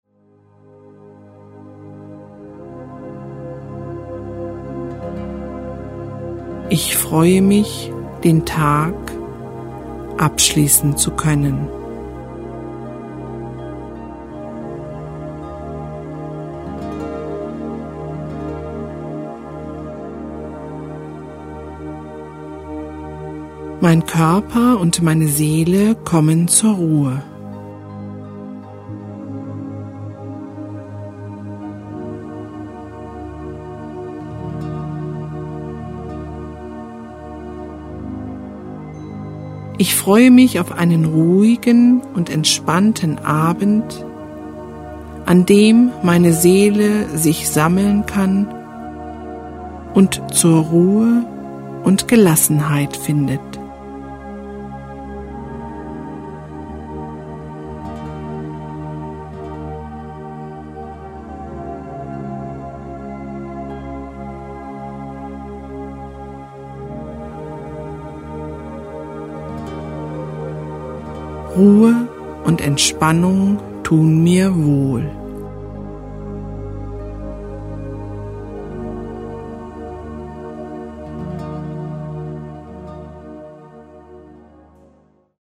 Die begleitende Musik beginnt belebter und wird nach und nach ruhiger.
Dem einen fällt die Entspannung bei einer tieferen männlichen Version leichter, den anderen inspiriert die weibliche ruhige Stimmlage.
Weibliche Stimme   11:59 min